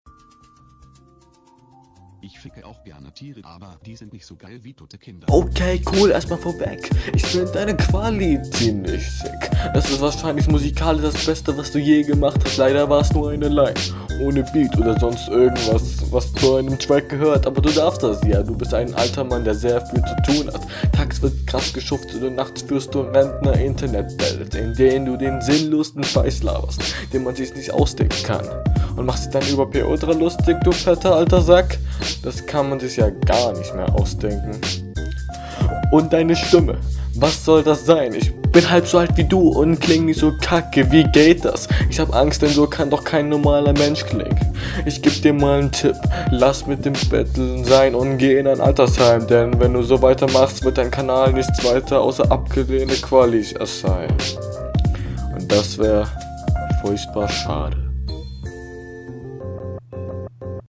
Flow: Der Stimmteinsatz ist nicht wirklich schlecht, aber die Silbensetzung und die länge der einzelnen …
Flow:Leider rappst du nicht ganz sauber von der aussprache her und dein stimmeinsatz ist quasi …